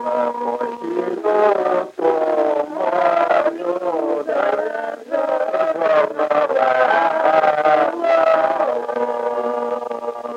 Оканье (полное оканье, свойственное Поморской группе севернорусского наречия – это различение гласных фонем /о/ и /а/ во всех безударных слогах)